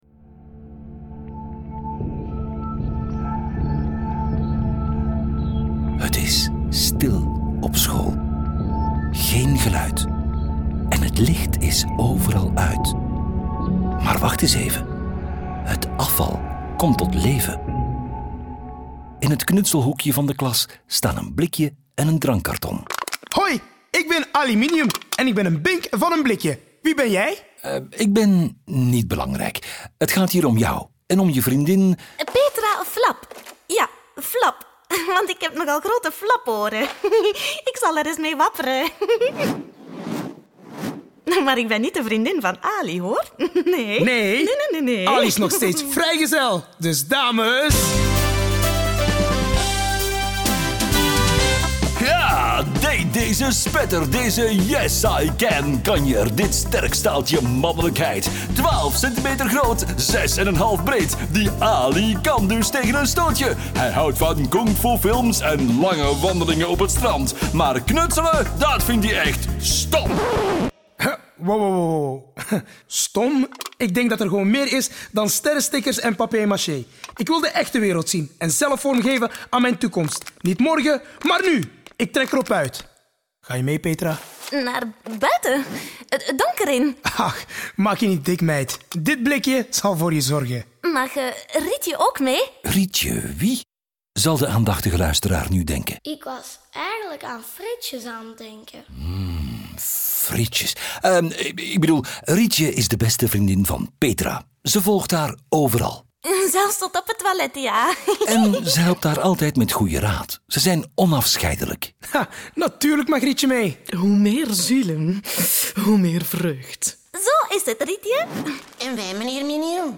Vlaams
Commercieel, Natuurlijk, Speels, Vertrouwd, Warm